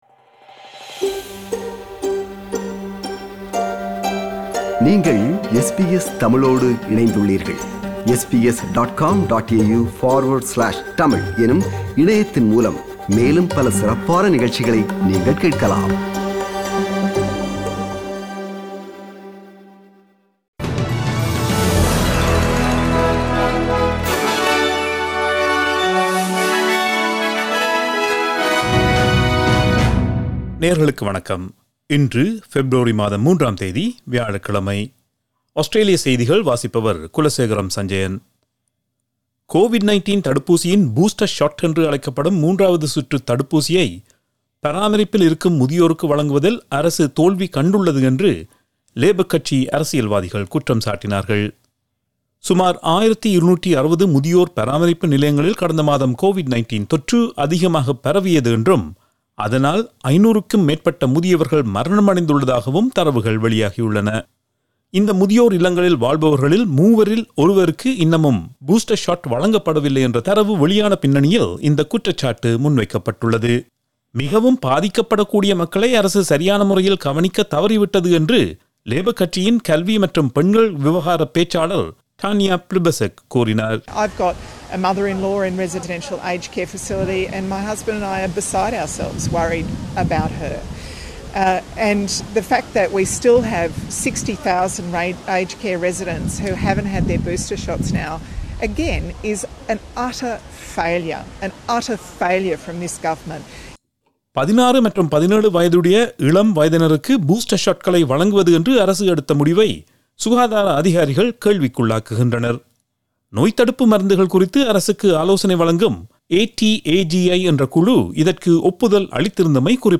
Australian news bulletin for Thursday 03 February 2022.